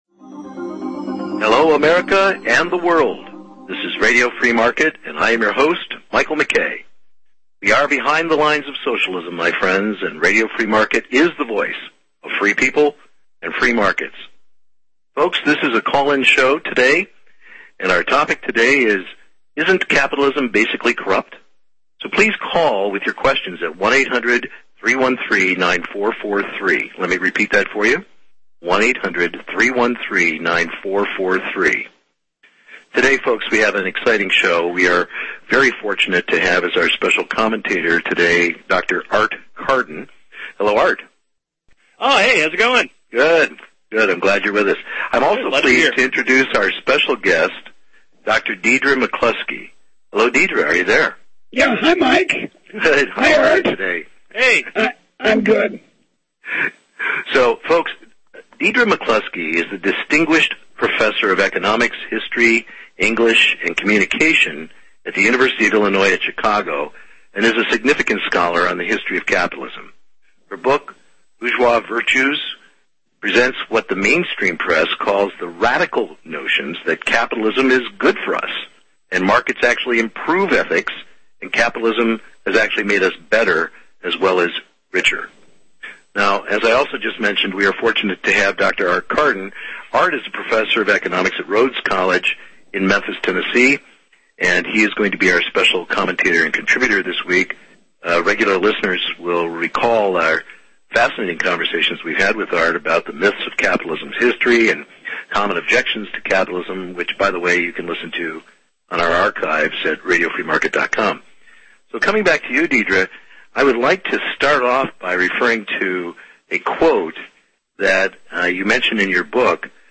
(Due to technical issues the sound quality of this interview is challenging. However the content makes it worth the effort.)